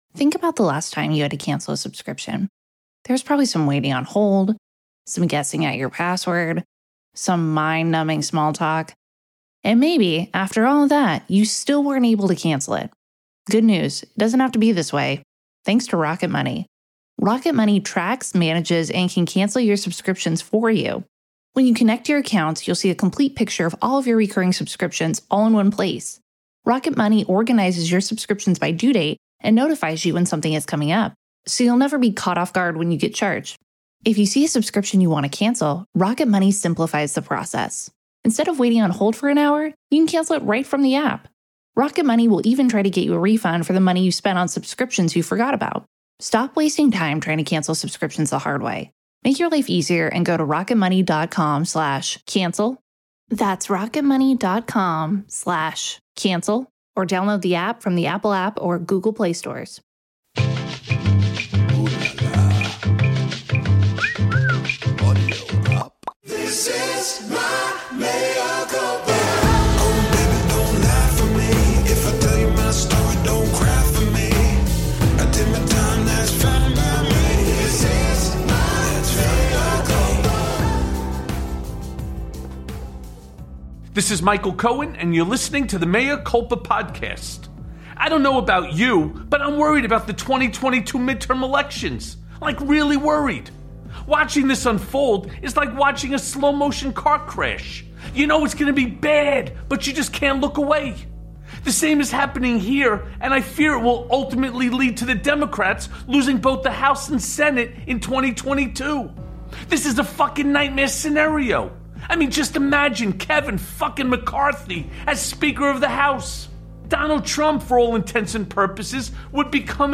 We speak with NY1 and CNN’s Errol Louis about Biden, the Dems and why he’s worried about 2022.